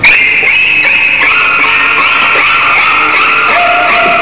violons).
violons stridents